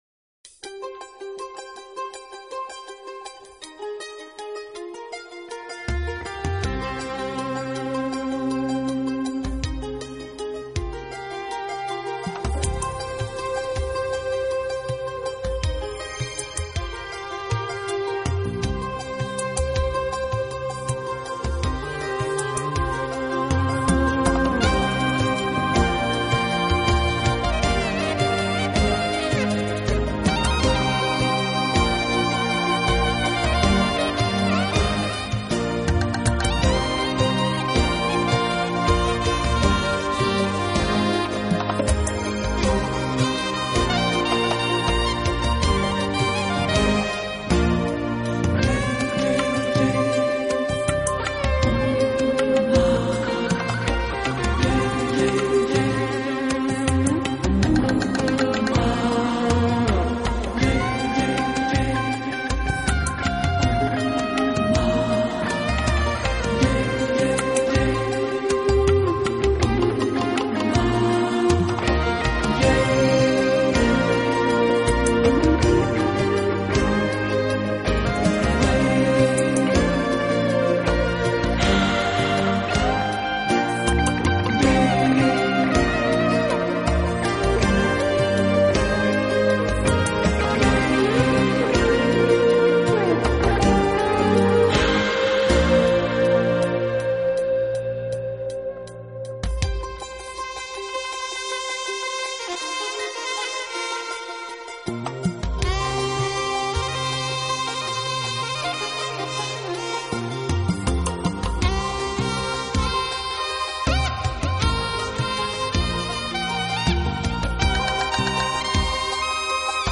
【音乐类型】New Age